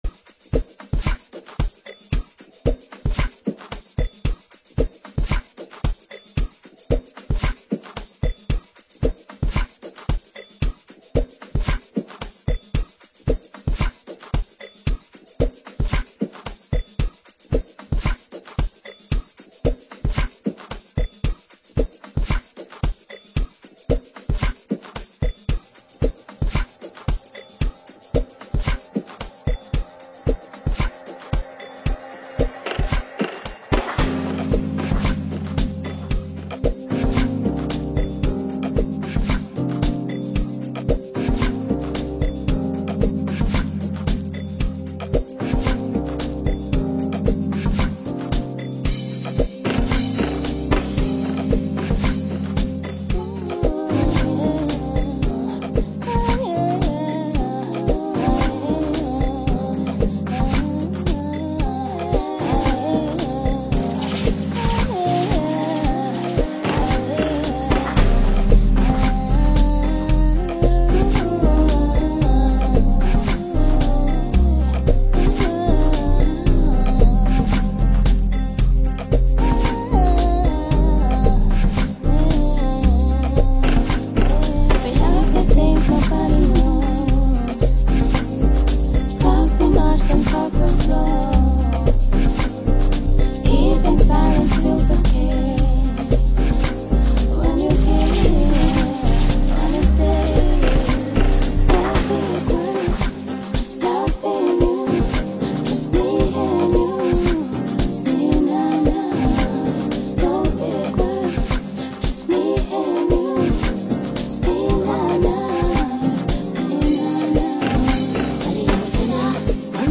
infectious rhythm